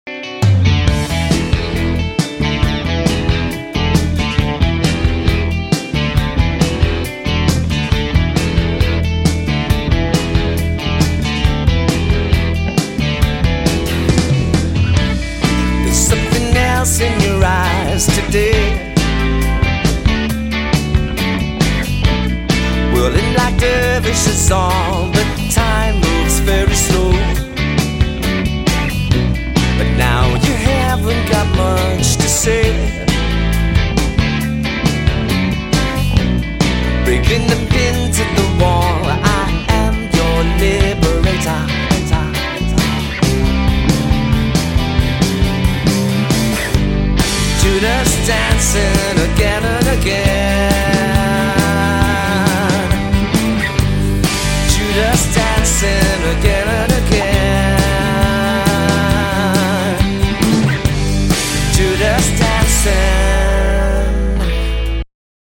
abotsa, gitarrak eta teklatuak
baxua
bateria, perkusioak eta abotsak